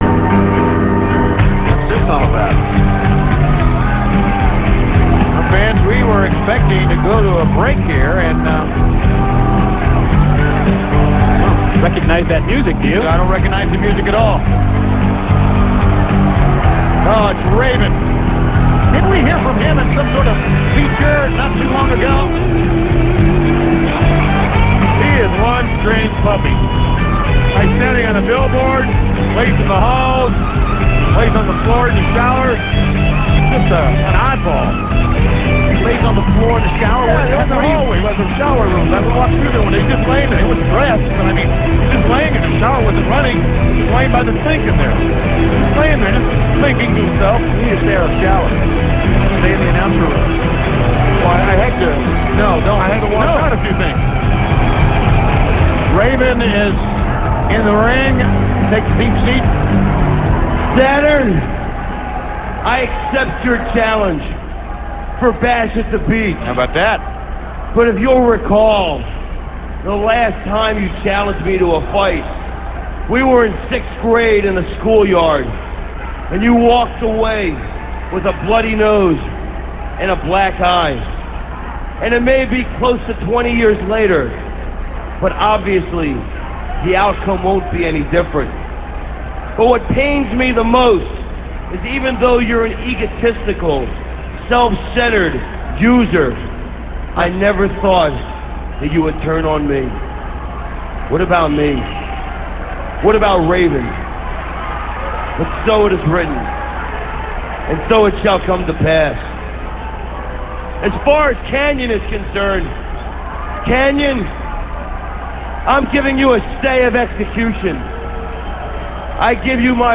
- This speech comes from Thunder - [7.2.98]. The debut of Raven's WCW theme music. Plus, Raven talks about how he accepts Saturn's challenge for Bash At The Beach, and how he'll beat him now as he did as a child.